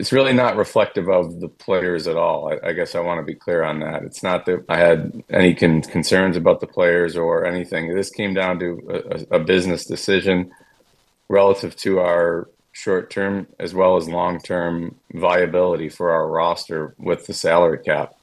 New GM for Edmonton Stan Bowman spoke to media following the decisions and said this was leaning towards a business move more than anything else.